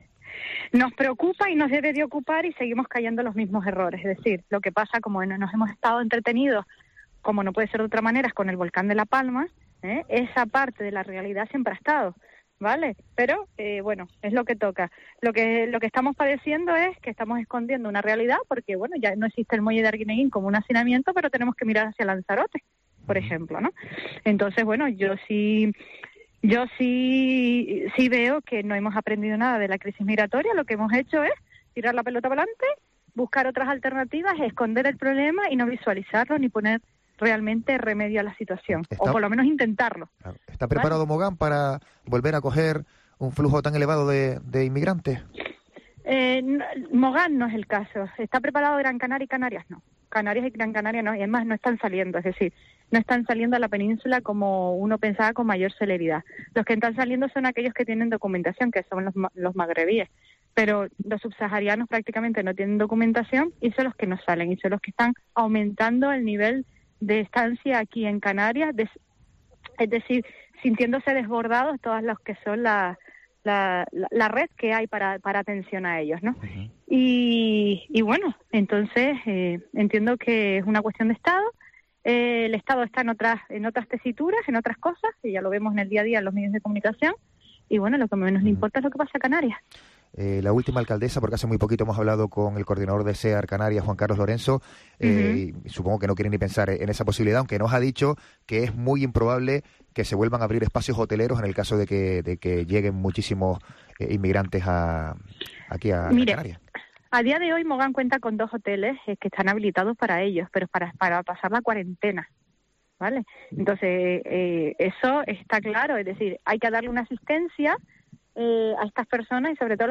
Onalia Bueno, alcaldesa de Mogán
Así se pronunciaba Onalia Bueno en los micrófonos de COPE Gran Canaria, cuando se le ha cuestionado por el repunte migratorio que se vive en las últimas semanas, aunque destacaba que es una realidad que siempre ha estado así, oculta por otras realidades como la erupción volcánica en La Palma.